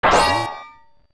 OoT Giant's Knife breaking
sound effect as the Giant's Knife breaking when the blade becomes dull after one hundred strikes.
OOT_GiantsKnife_Break.wav